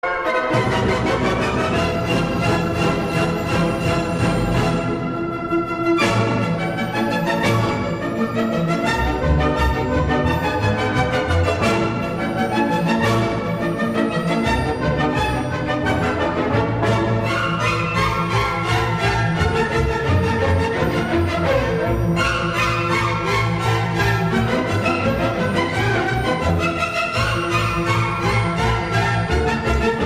You will hear better instrument separation, better dynamics, and a detailed and crisp reproduction of the original sound.
Note: The sound quality of the audio files that I am sharing below depends on multiple factors, i.e., the microphone from which it’s recorded, the quality of your speakers where you are listening to the audio, the turntable setup, and the mastering of the original audio.
Ortofon 2M Blue MM Cartridge: